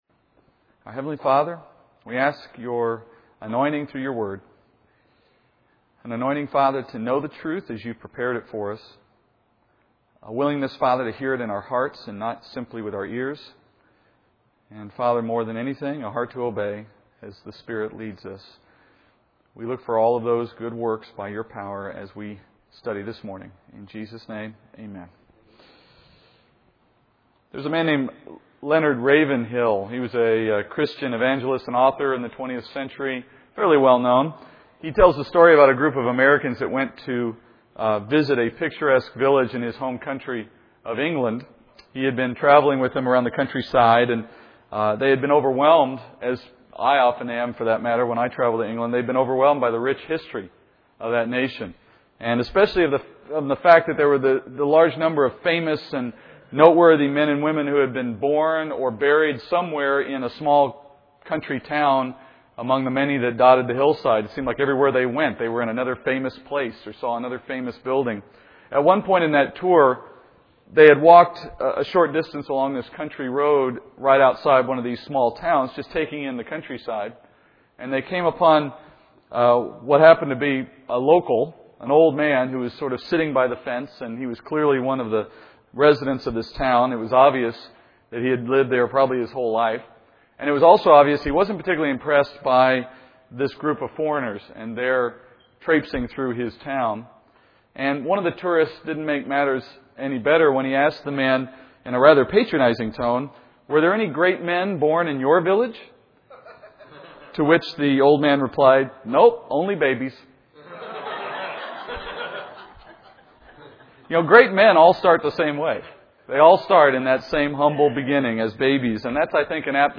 Restoring God’s People - Lesson 6 | Verse By Verse Ministry International